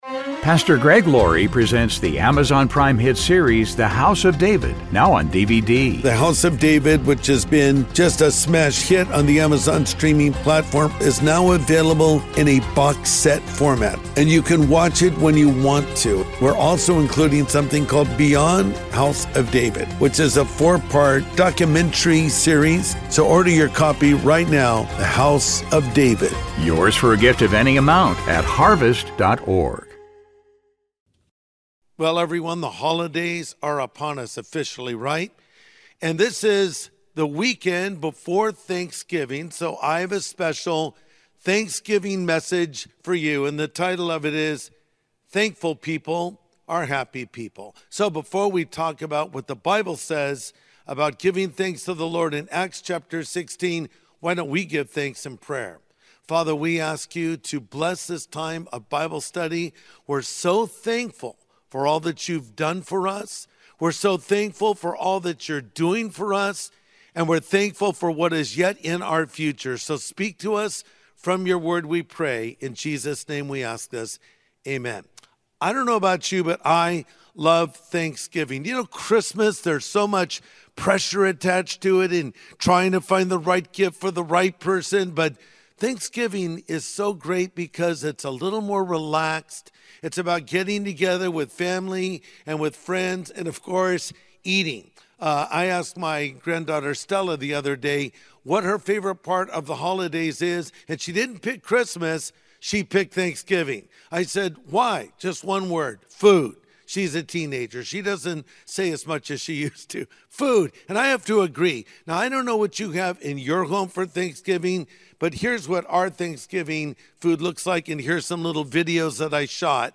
This Thanksgiving, we must be thankful for the things that we have but also for the things that God has in store for us! In this Sunday message from the archives, Pastor Greg Laurie shares a powerful example of praising God in all circumstances. Listen in to a special message from Acts 16.